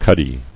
[cud·dy]